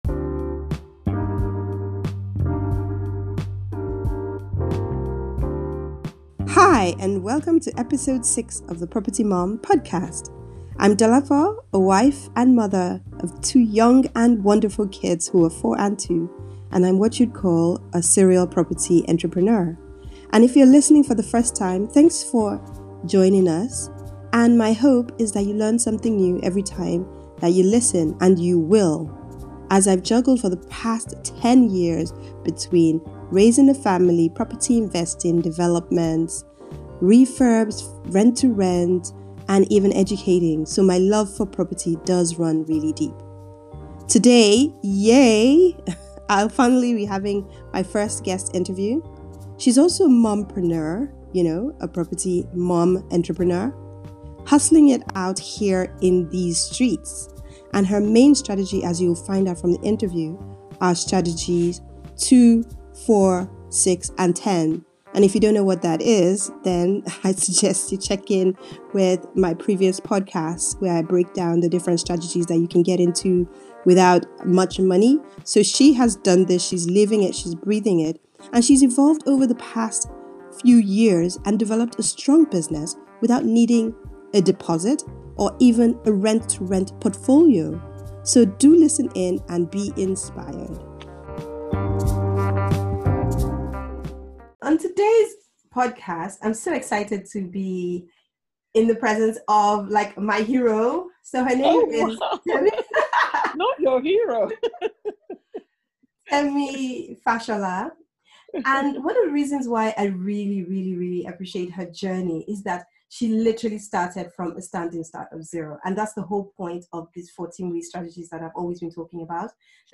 Property MomPreneurs: Guest Interview